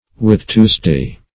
\Whit"tues`day\
whit-tuesday.mp3